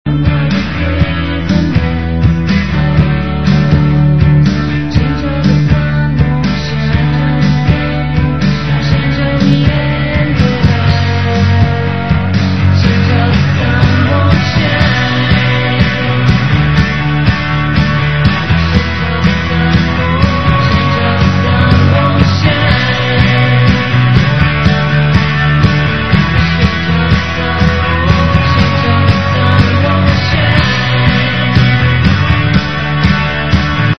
Vocals
Guitars
Drums
Bass